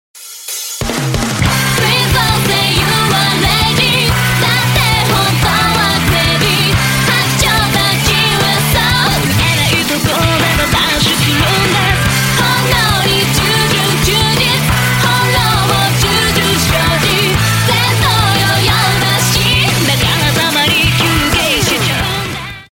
Рок Металл Рингтоны